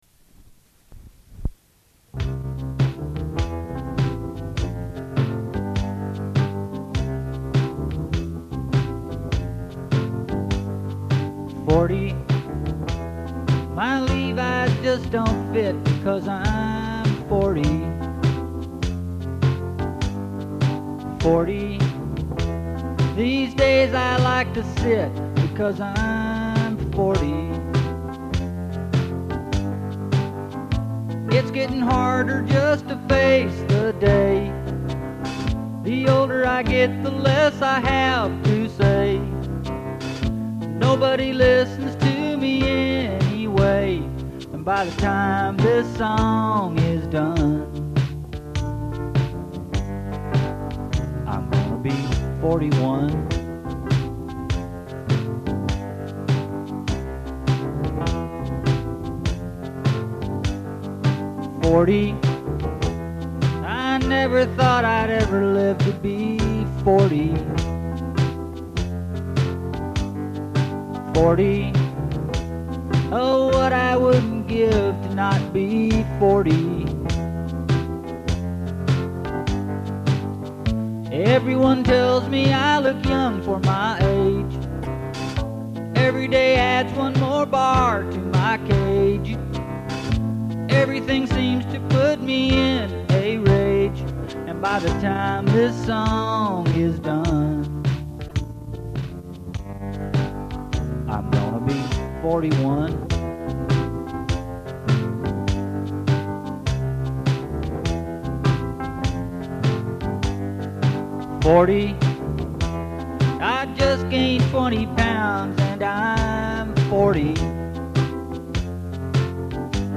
4 TRACK DEMO